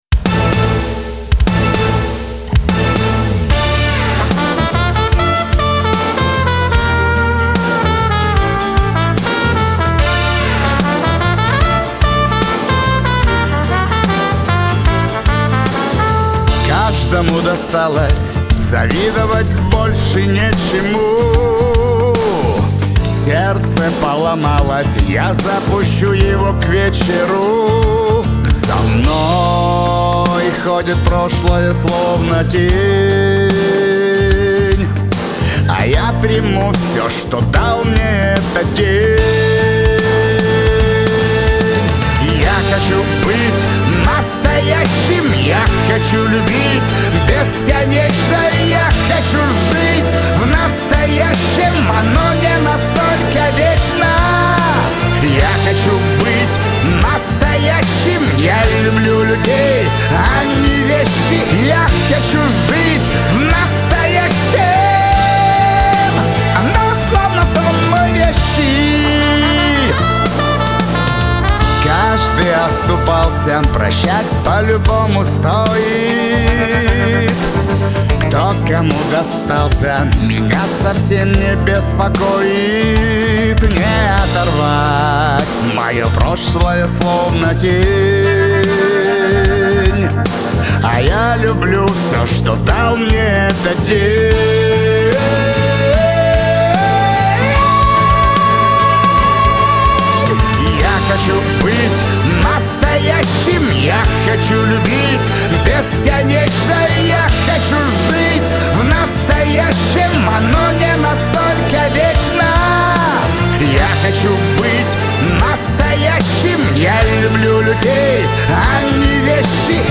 Трек размещён в разделе Русские песни / Эстрада / 2022.